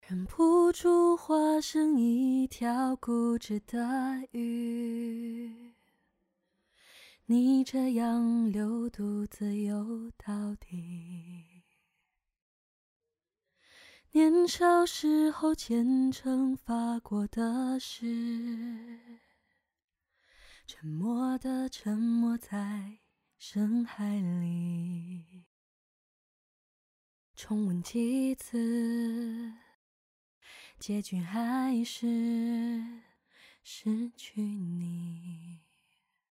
歌曲调式：F小调
干声数量：1轨道
干声试听